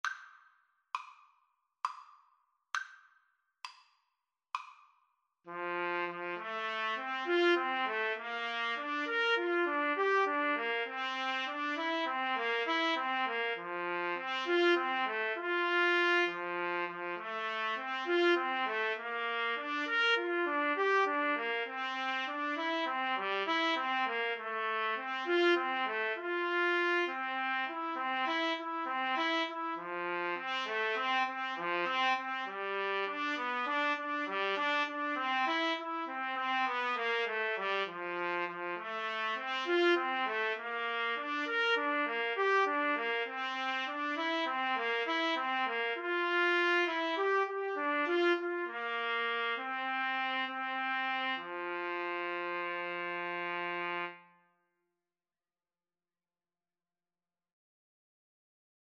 Moderato
9/8 (View more 9/8 Music)